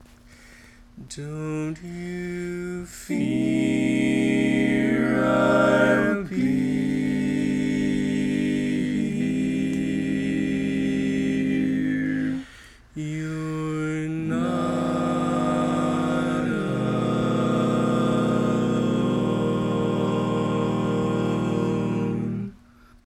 Key written in: E♭ Major
How many parts: 4
Type: Barbershop
All Parts mix: